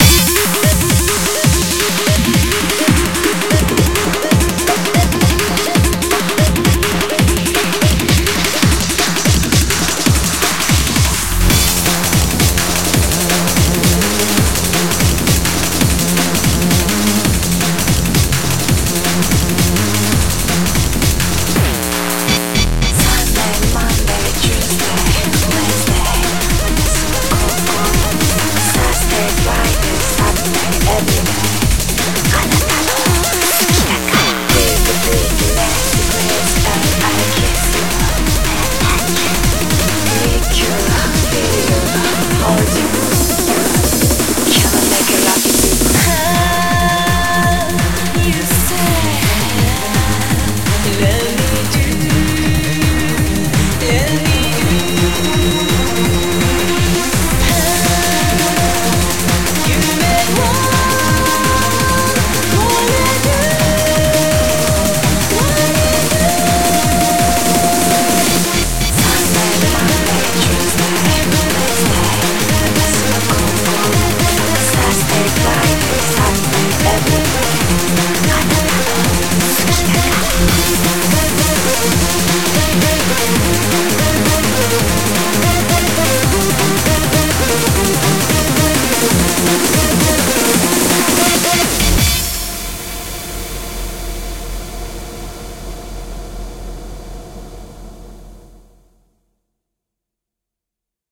BPM167
Audio QualityPerfect (Low Quality)